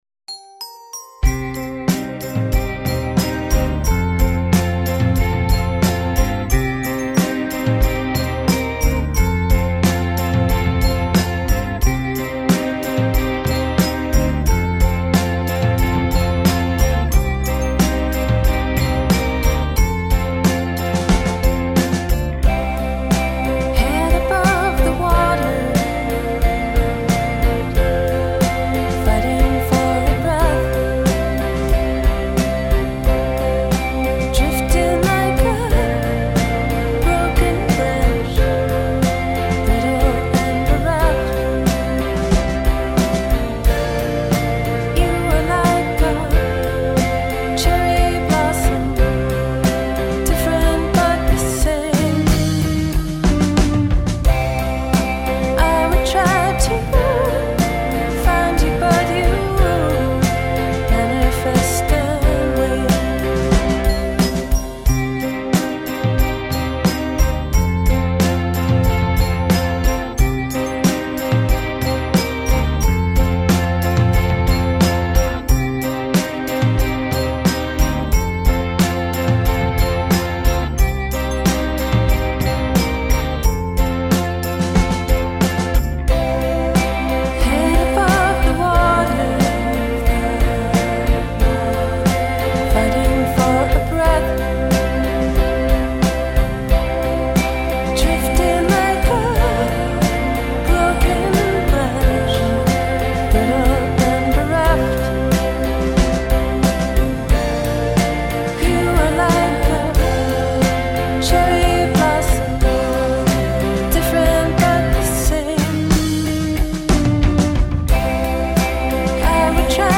Dreamy, slightly mournful indie pop.
Tagged as: Electro Rock, Pop